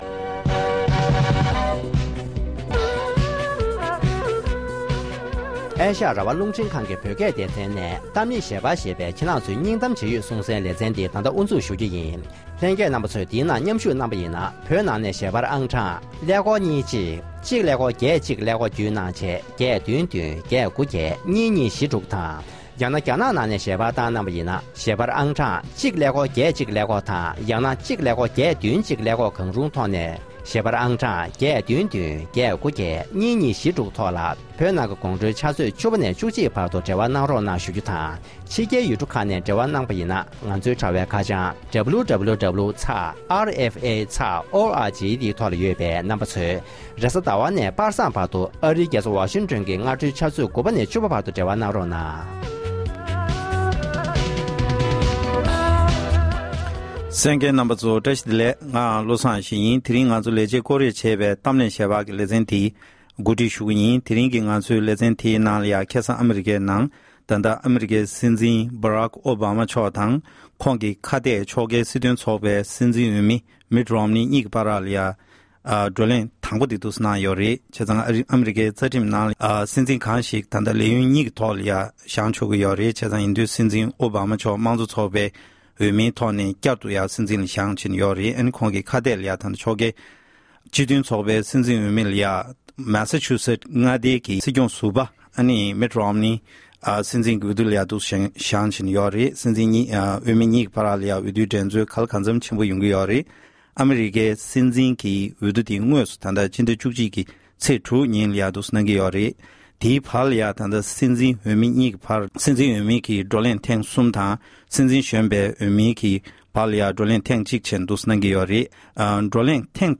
གཏམ་གླེང་ཞལ་པར